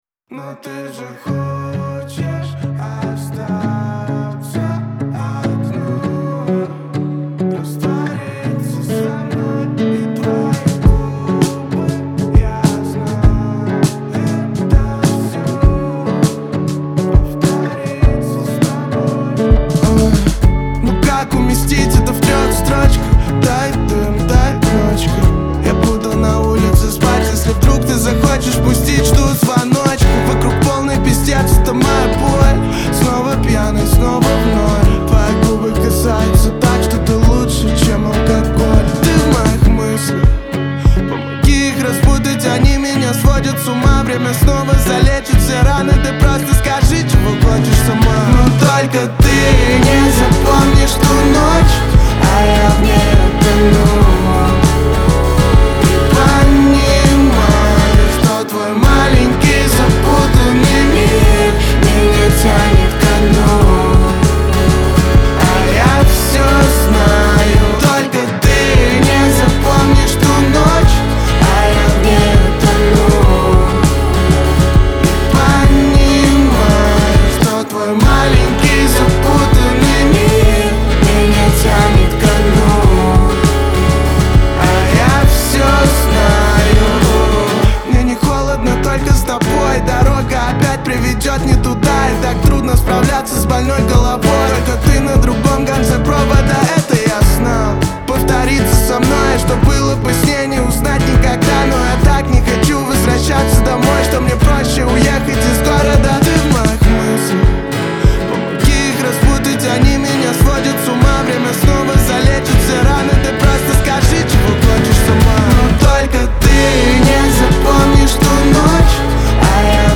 Русские новинки